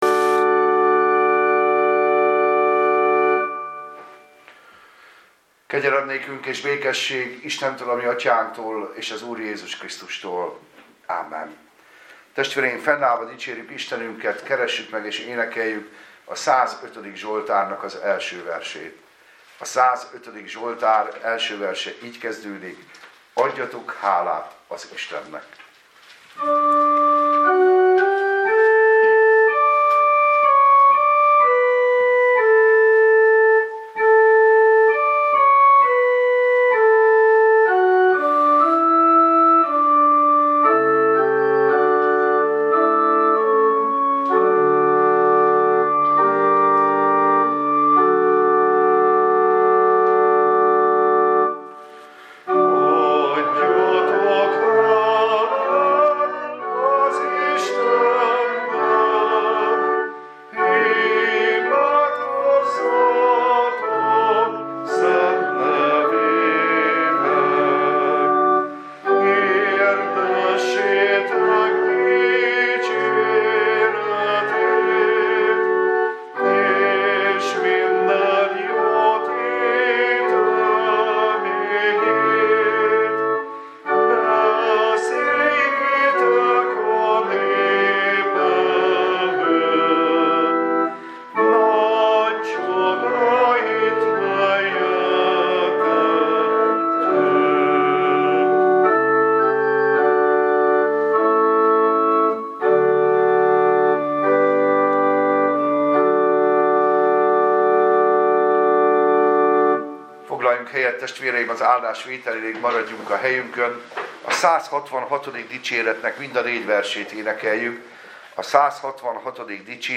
Újévi istentisztelet